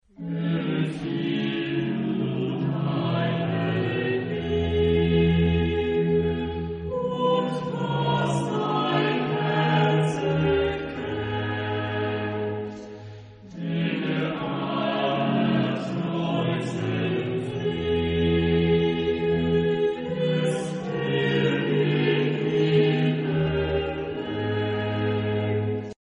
Genre-Style-Form: Chorale ; Sacred
Type of Choir: SATB  (4 mixed voices )
Instruments: Organ (ad lib)
Tonality: D minor